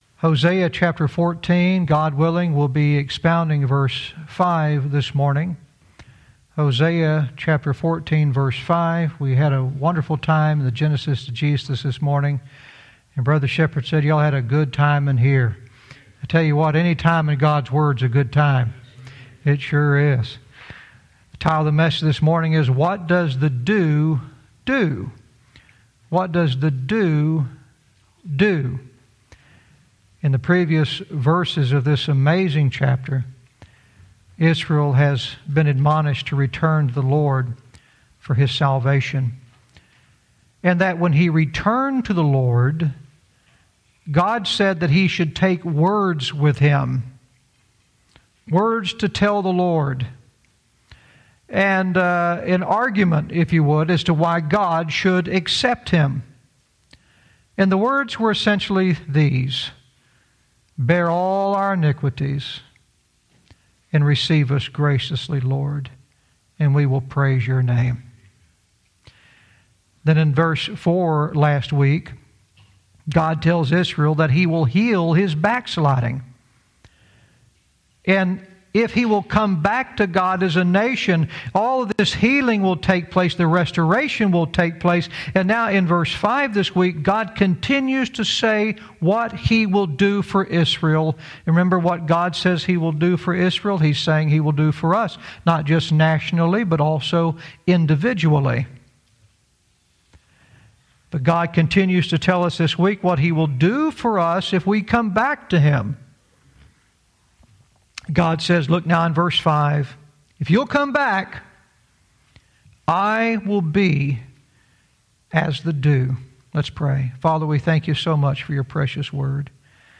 Verse by verse teaching - Hosea 14:5 "What Does the Dew Do?"